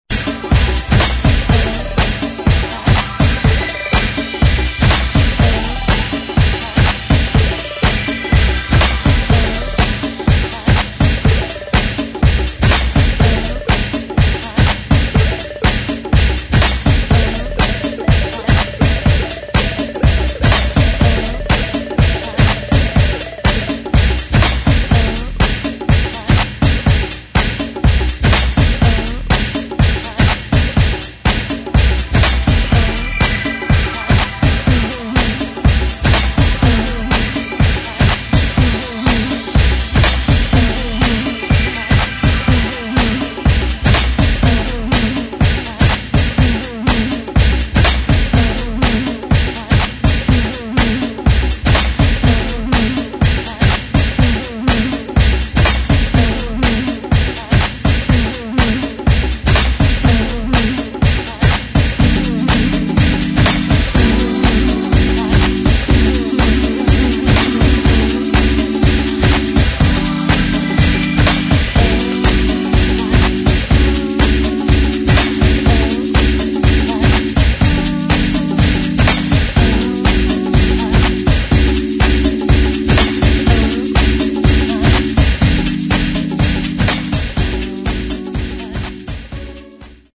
[ DEEP HOUSE | TECHNO ]